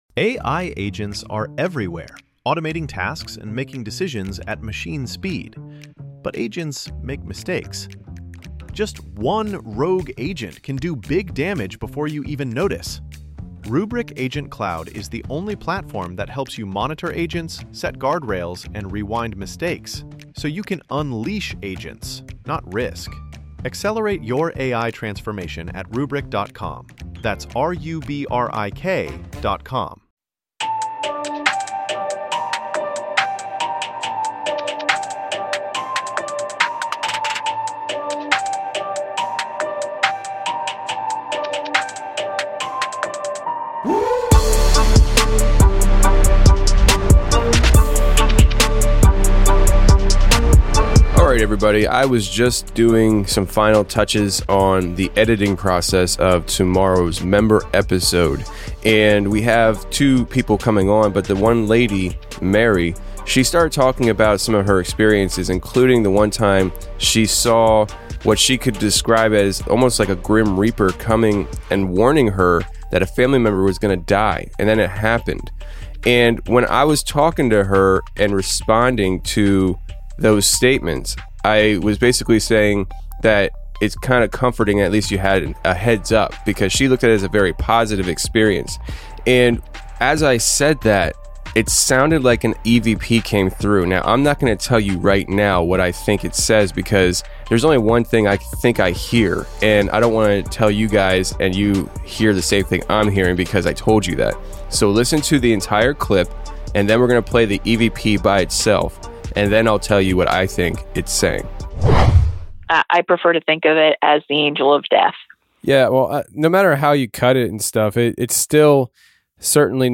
Is it an otherworldly voice? The guest talking quietly? A technical glitch?